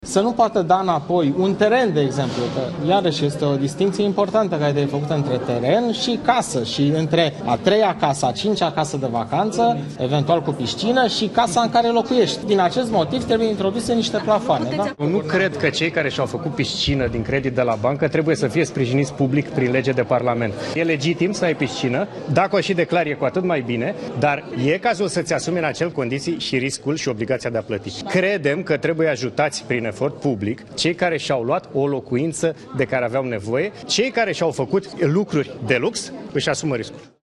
Viceguvernatorii BNR – Liviu Voinea și Bogdan Olteanu – au încercat să le explice jurnaliștilor aceste propuneri: